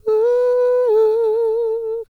E-CROON P332.wav